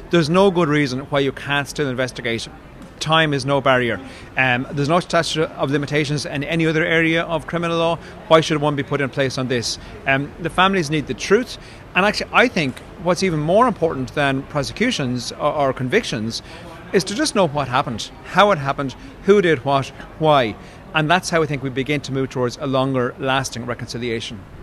Fianna Fáil TD James Lawless, the chairman of the Oireachtas justice committee, says historical crimes can still be solved: